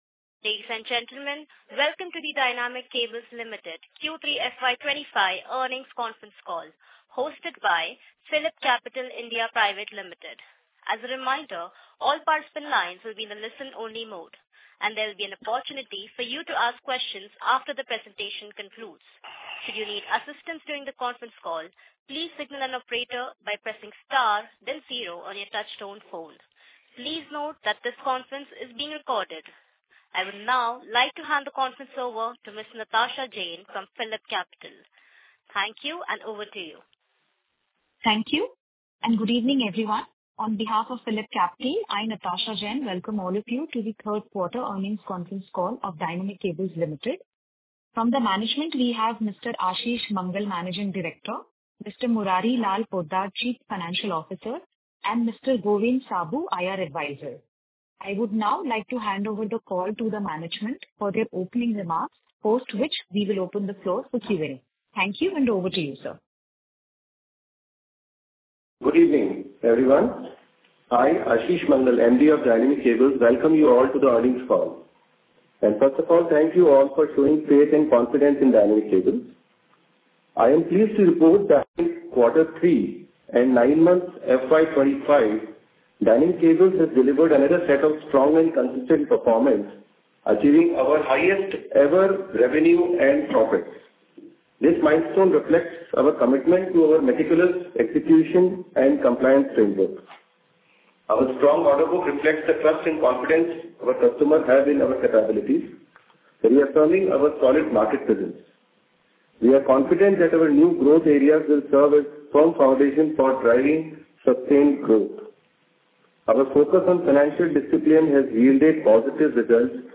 Recording of Post earning Calls.
Audio Recording for conference call held on January 28,2025 wherein Management of DYCL discussed the Company's results for the quarter and nine months ended December 31, 2024.